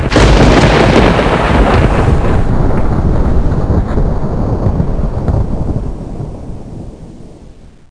1 channel
shortThunderCrack.mp3